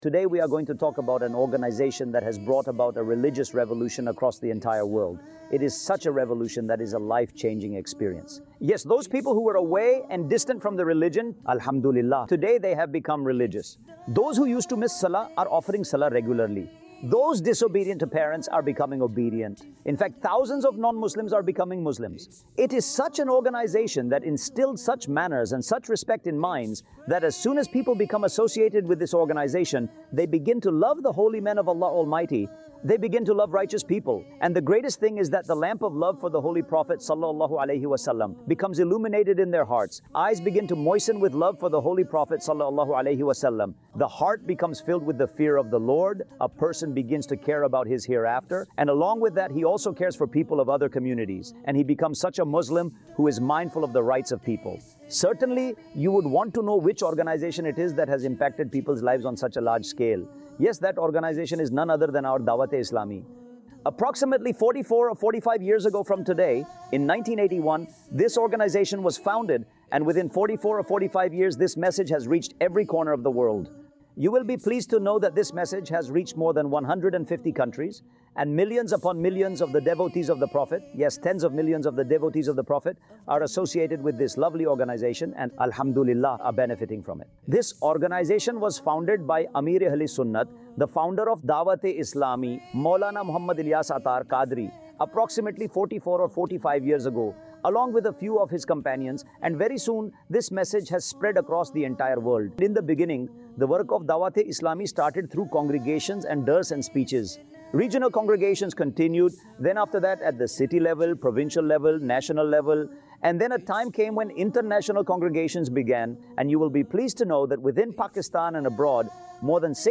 Introduction of Dawateislami | AI Generated Audio | 17 Minutes Documentary 2026
اے آئی جنریٹڈ آڈیو